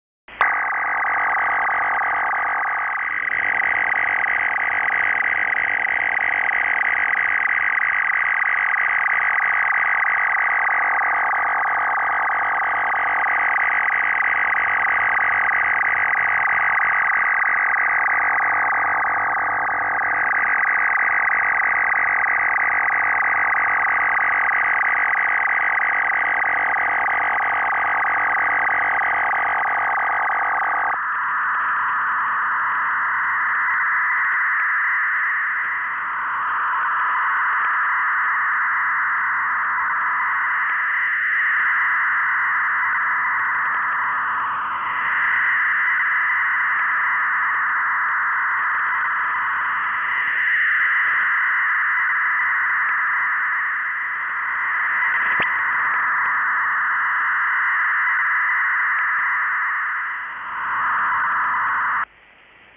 BPSK 1000 Bd
1000 Bd BPSK WAVEFORM (SERIAL) (Unidentified User / possibly Russian origin) AUDIO SAMPLES 4-FSK 200 Bd call-up sequence with traffic sent using 1000 Bd BPSK (large file with appx 1.3 MB!) back to PSK-systems page